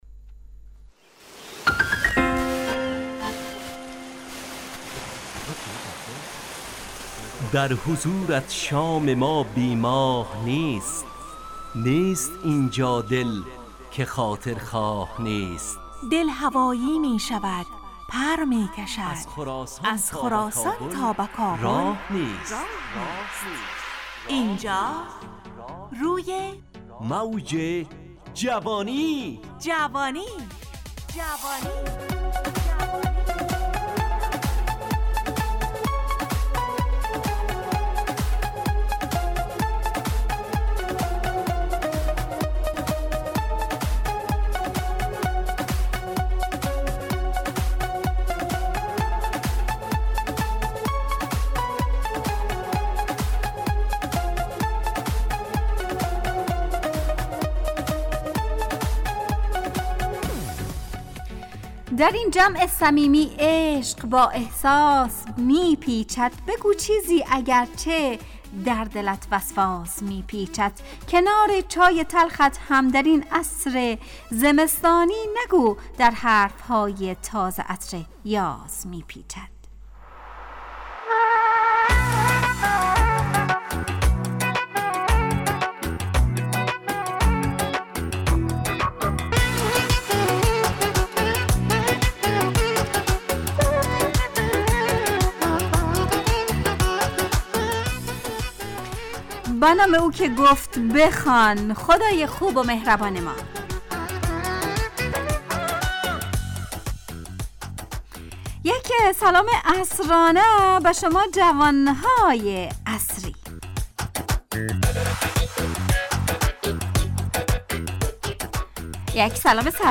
روی موج جوانی، برنامه شادو عصرانه رادیودری.
همراه با ترانه و موسیقی مدت برنامه 55 دقیقه . بحث محوری این هفته (سواد) تهیه کننده